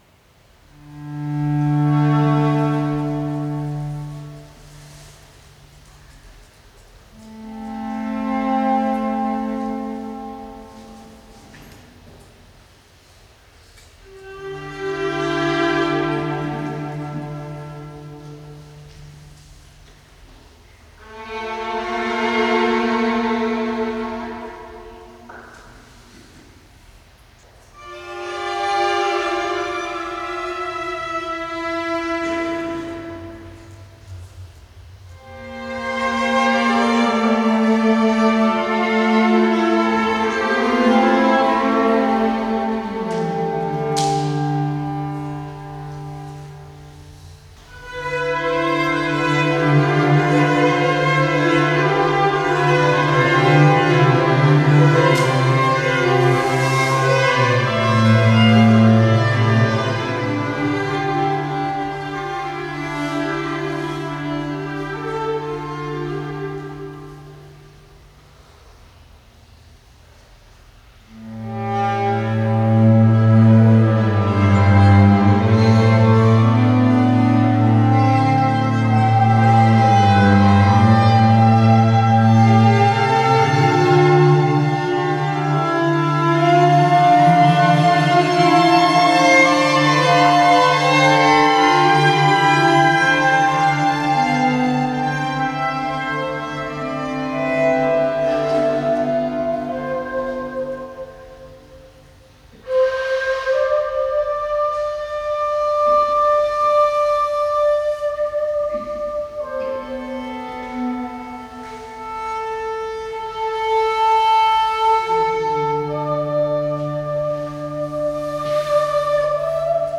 cello, harmonic whirlies, overtone singing
shakuhachi
violin
viola